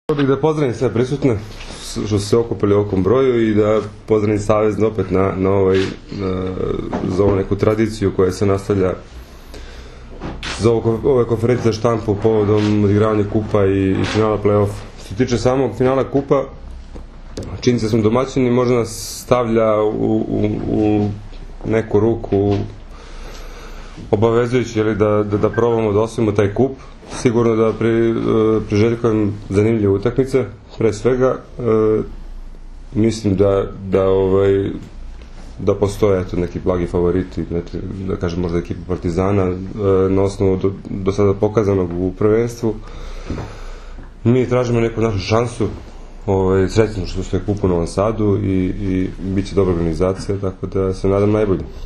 U prostorijama Odbojkaškog saveza Srbije danas je održana konferencija za novinare povodom Finalnog turnira jubilarnog, 50. Kupa Srbije.
IZJAVA